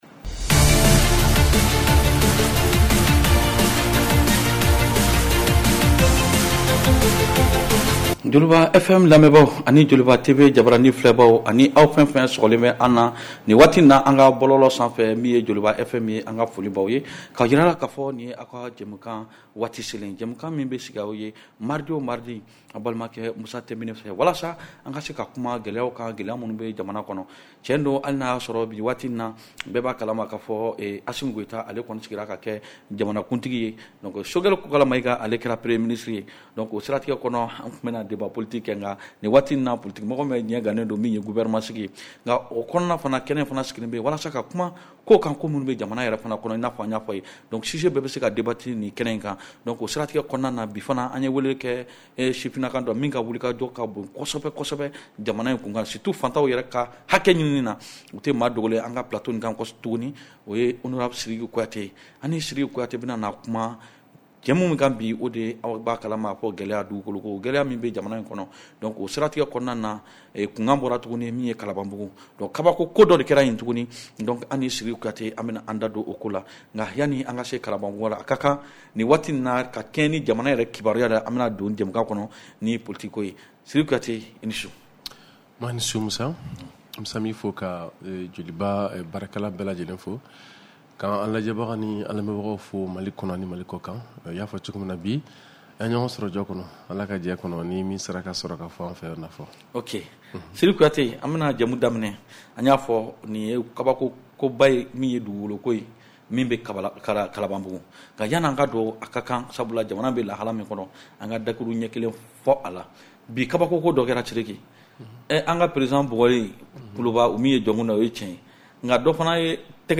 Réécoutez votre émission de débat politique en bambara.